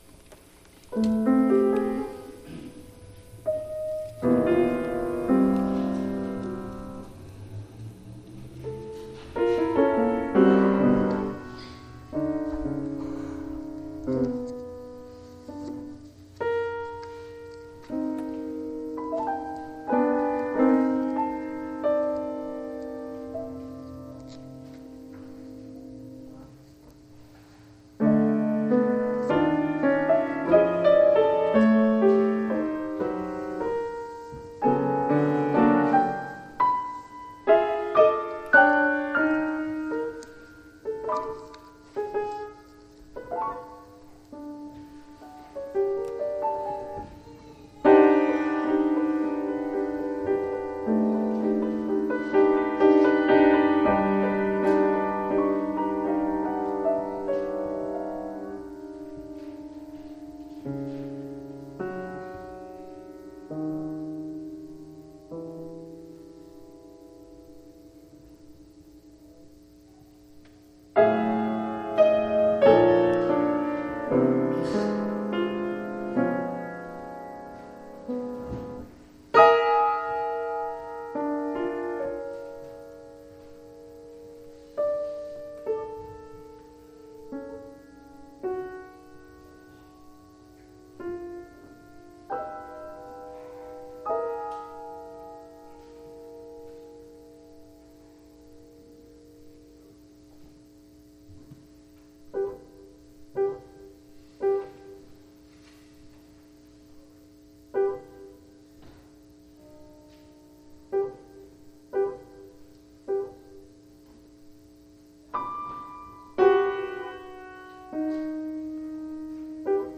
OFFERTORY -  Sechs kleine Klavierstucke (Six Little Piano Peces) Op.19, 1911
The atonality in these miniatures predates Schoenberg's later